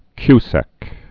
(kysĕk)